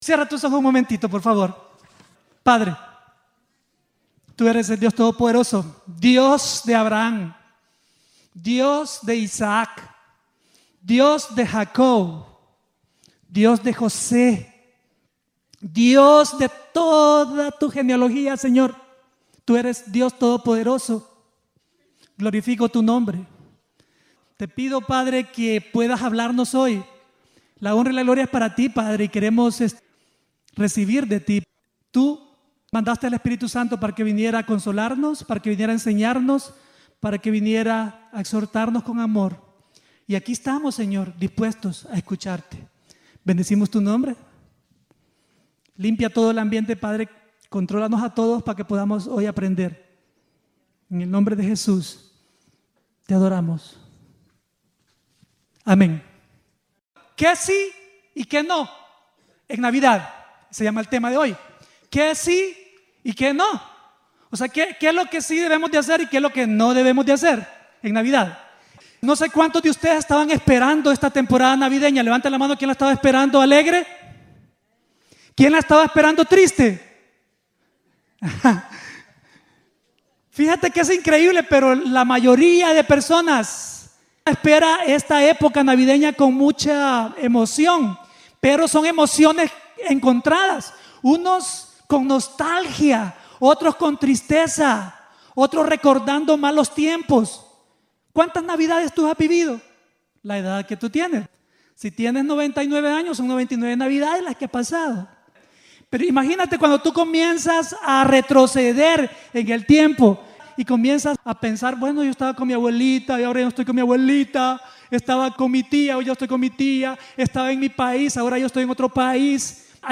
predica sobre si un hijo de Dios puede o no celebrar la Navidad